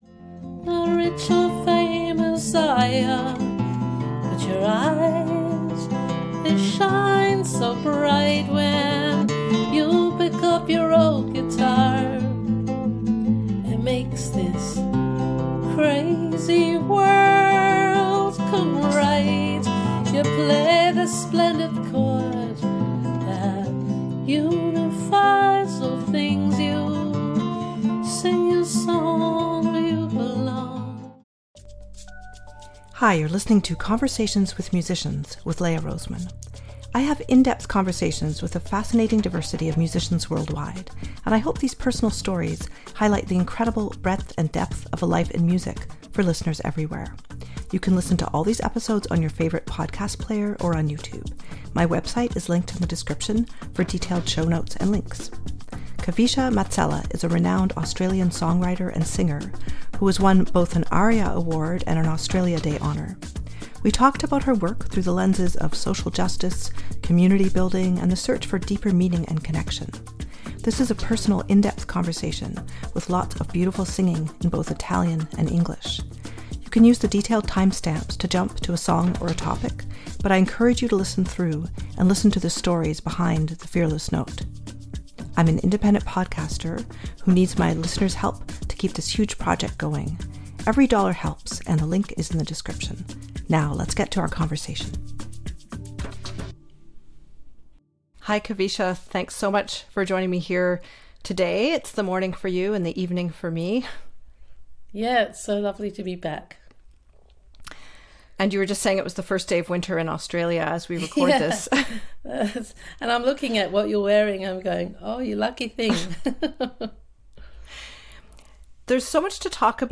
We talked about her work through the lenses of social justice, community building, and the search for deeper meaning and connection. This is a personal, in-depth conversation with lots of beautiful singing in both Italian and English.